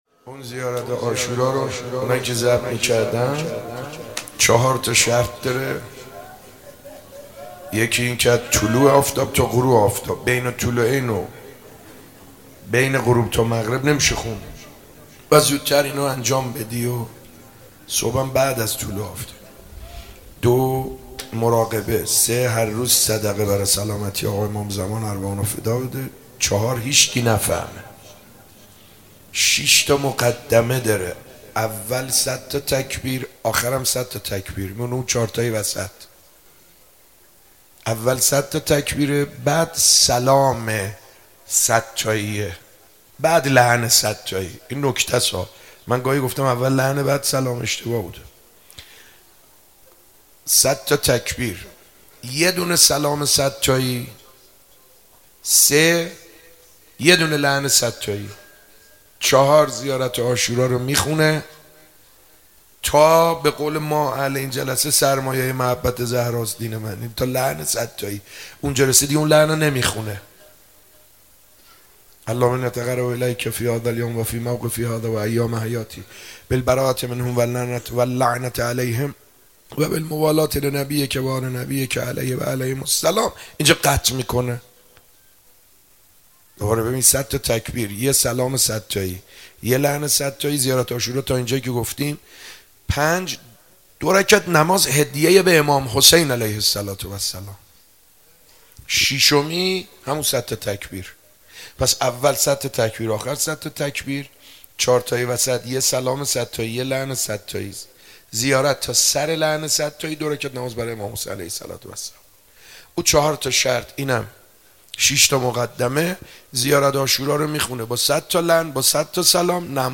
شب 29 ماه مبارک رمضان 95_صحبت